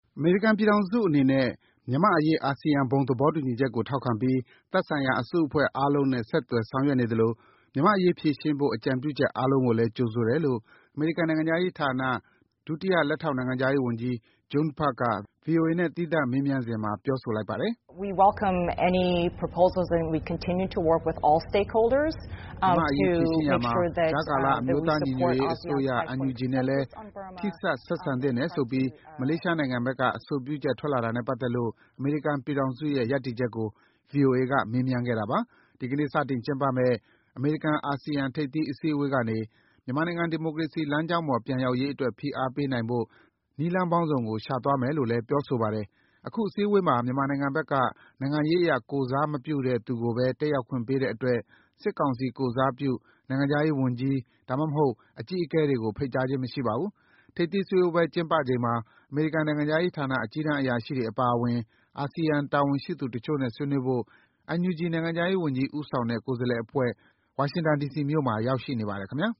အမေရိကန်အနေနဲ့ မြန်မာ့အရေးအာဆီယံဘုံသဘောတူညီချက်ကို ထောက်ခံပြီး သက်ဆိုင်ရာ အစုအဖွဲ့အားလုံးနဲ့ ဆက်သွယ်ဆောင်ရွက်နေသလို မြန်မာ့အရေးဖြေရှင်းဖို့ အကြံပြုချက်အားလုံးကိုလည်း ကြိုဆိုတယ်လို့ အမေရိကန်နိုင်ငံခြားရေးဌာန ဒုတိယလက်ထောက်နိုင်ငံခြားရေးဝန်ကြီး Jung Pak က VOA နဲ့ သီးသန့်မေးမြန်းစဉ်မှာပြောဆိုပါတယ်။